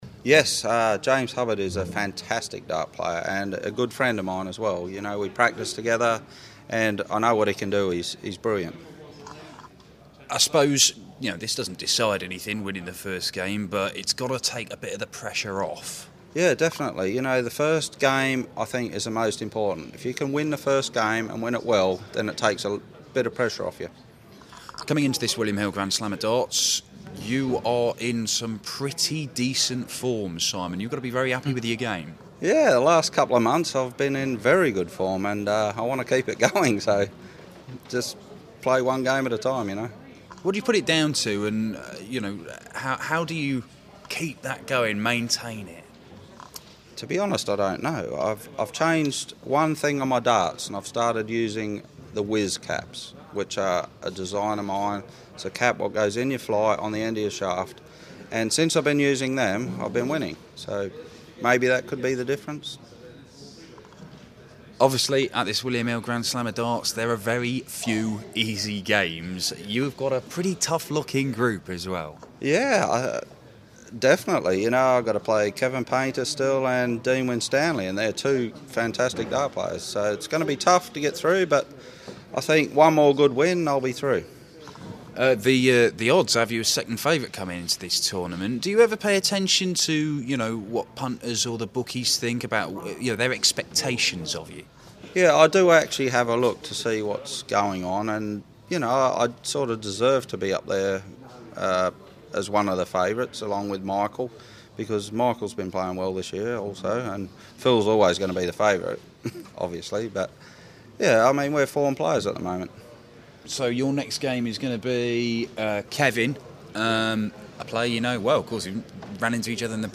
William Hill GSOD - Whitlock Interview